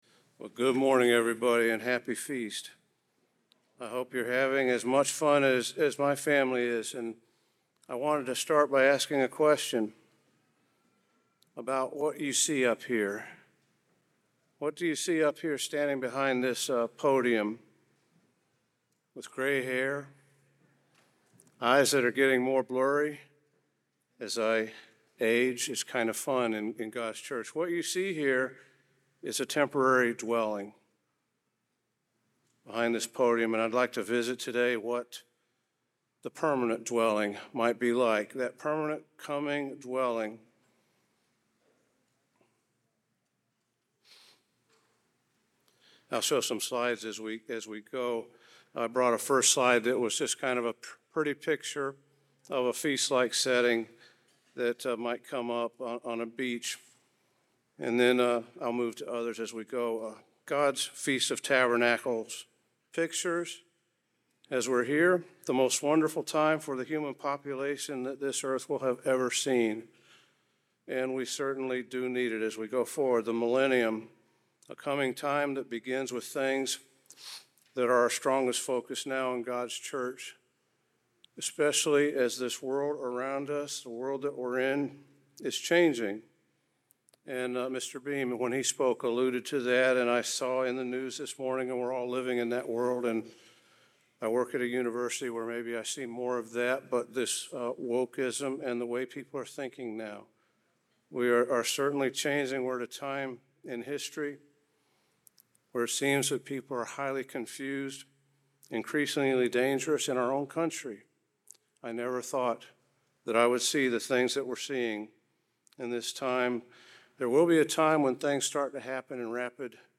This sermon was given at the Panama City Beach, Florida 2022 Feast site.